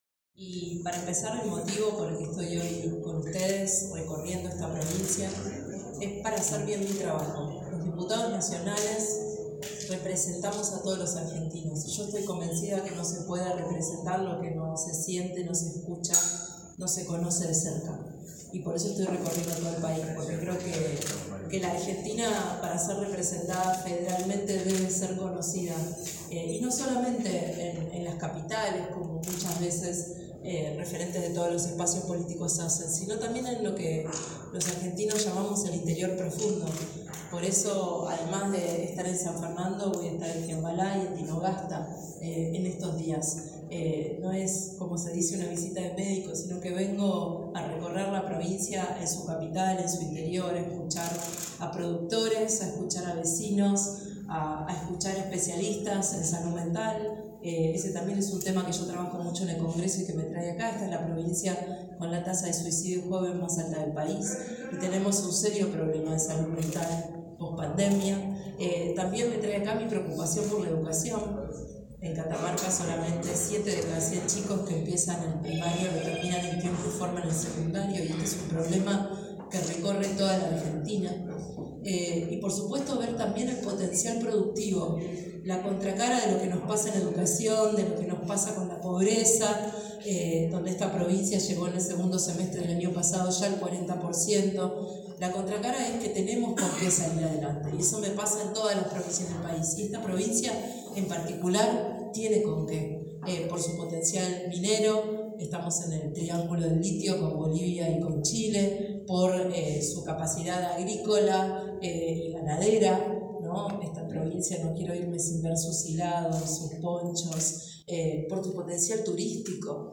“La Argentina para ser representada federalmente debe ser conocida y no solamente en las Capitales, como muchas veces referentes de todos los espacios políticos hacen”, manifestó a la prensa desde la sede del PRO, ubicada en Mota Botello al 758.